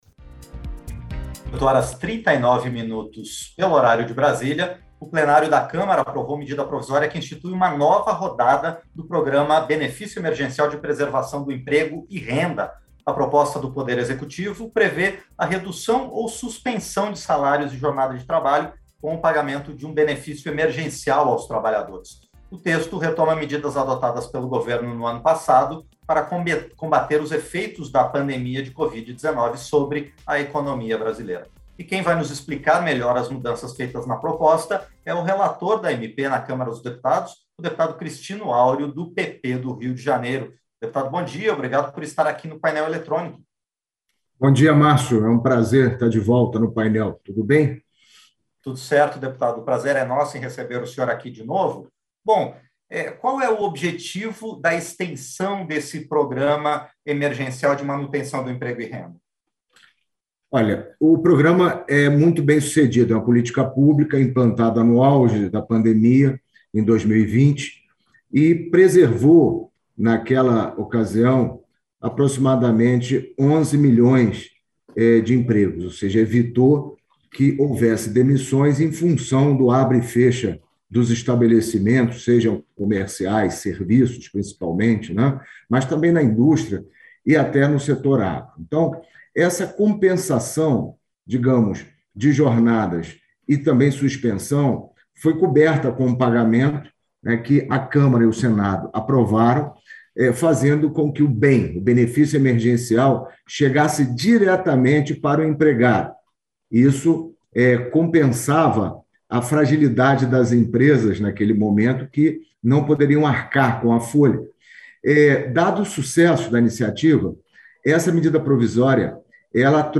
Entrevistas - Dep. Christino Áureo (PP-RJ)